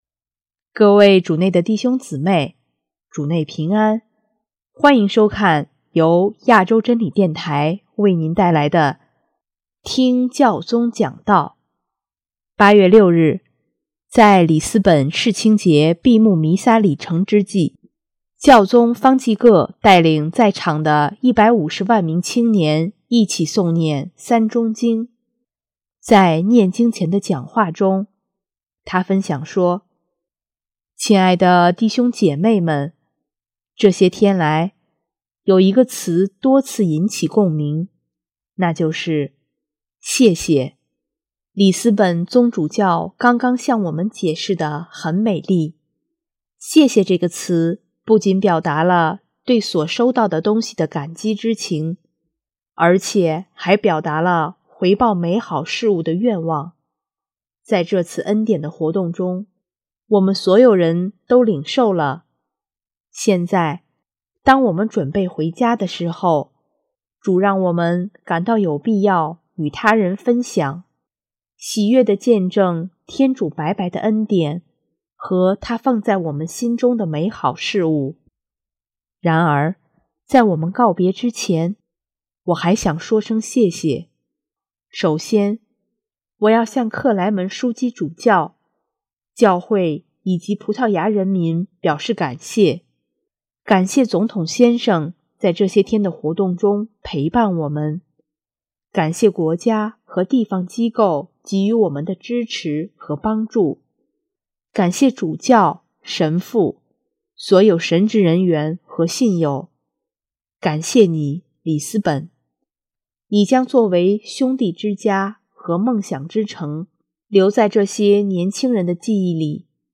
8月6日，在里斯本世青节闭幕弥撒礼成之际，教宗方济各带领在场的150万名青年一起诵念《三钟经》，在念经前的讲话中，他分享说：